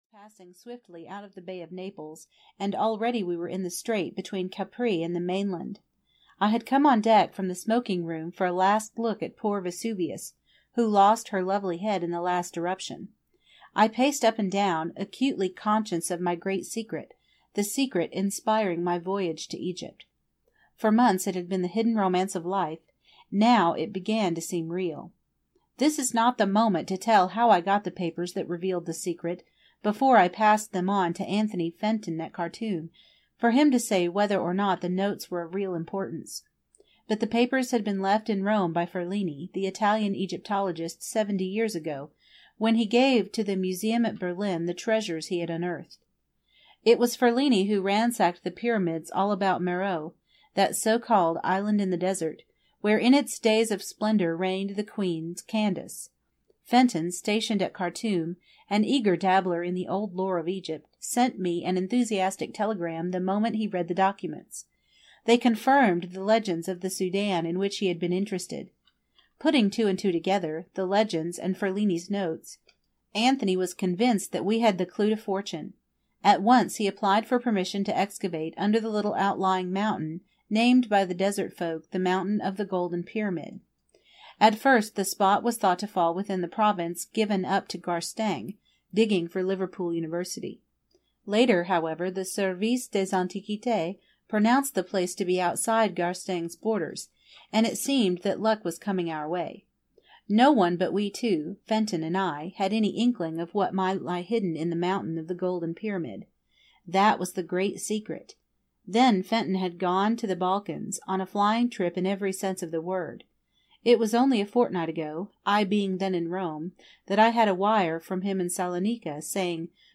It Happened in Egypt (EN) audiokniha
Ukázka z knihy